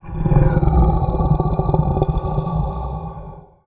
MONSTER_Growl_Breath_02_mono.wav